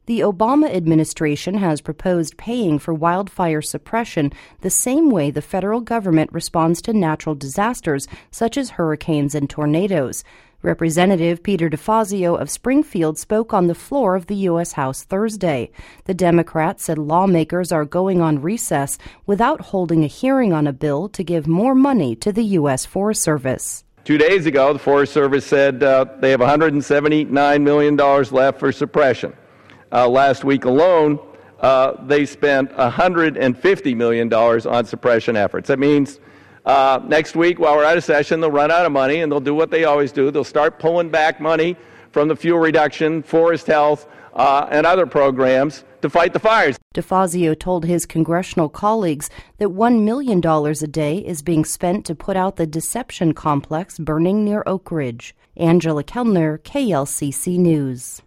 Representative Peter DeFazio of Springfield spoke on the floor of the U.S. House Thursday.